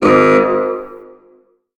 Sfx_creature_chelicerate_seatruckattack_alarm_01.ogg